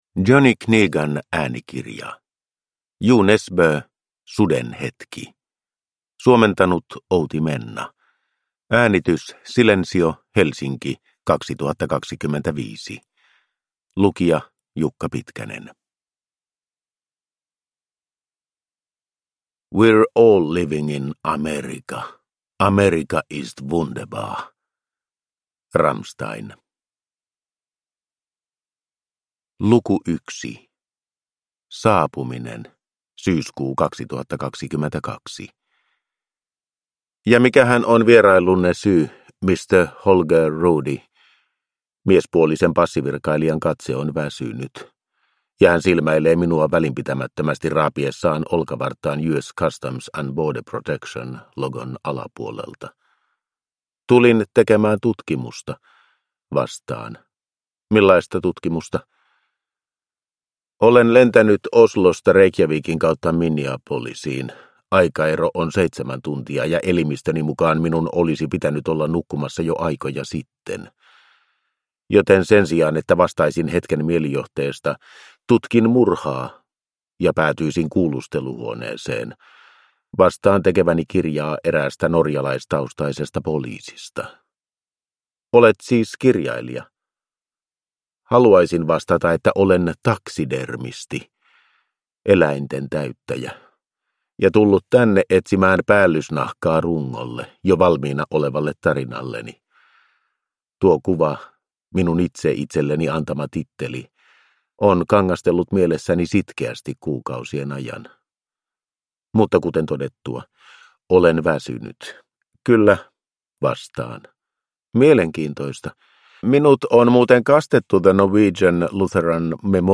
Suden hetki – Ljudbok